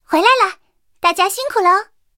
三号战斗返回语音.OGG